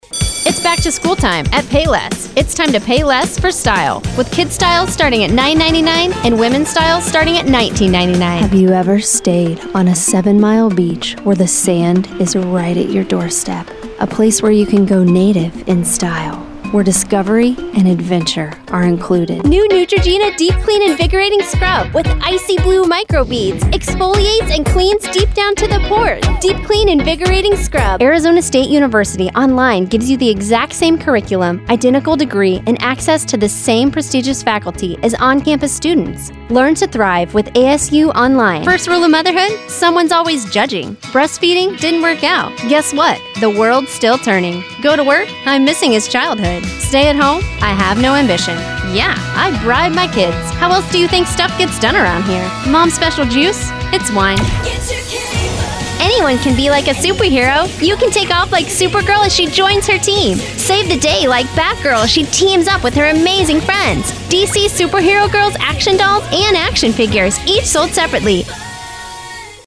Browse professional voiceover demos.
My voice is very feminine and soft.